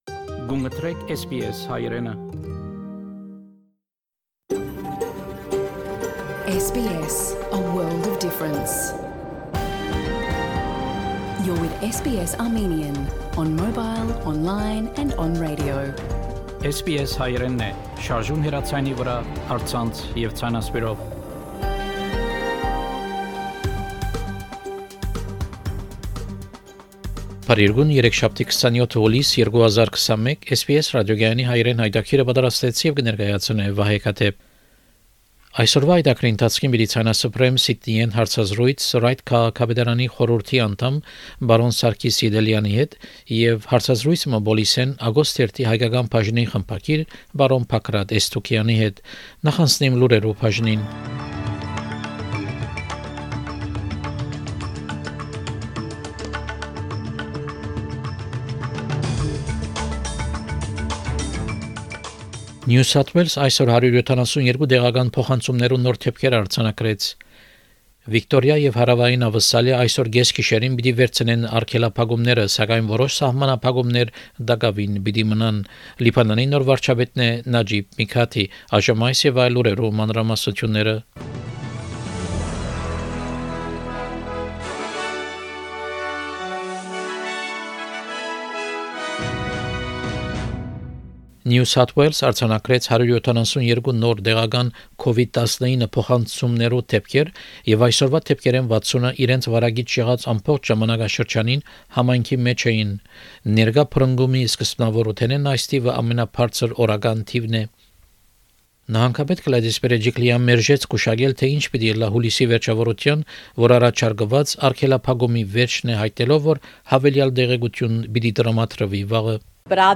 SBS Armenian news bulletin from 27 July 2021 program.